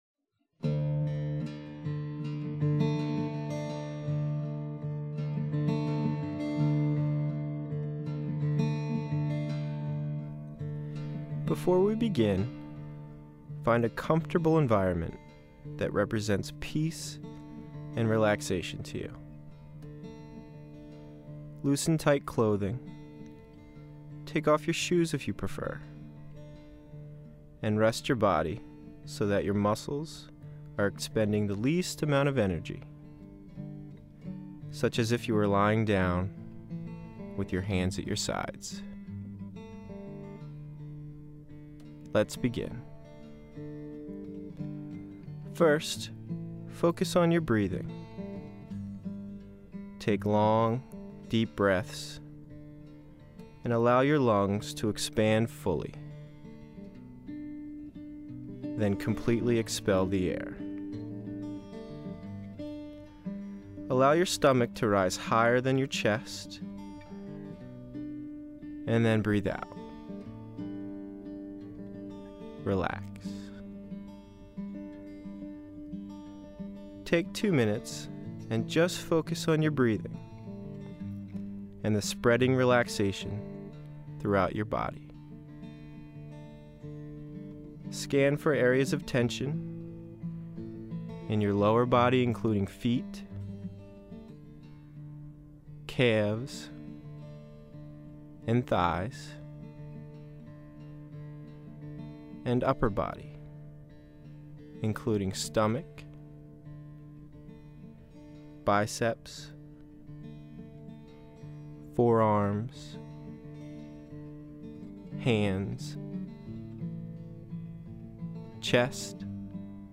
Performance Meditation - Health Services, University of New Hampshire ( Read More, 9:24 Mins, 8.8MB)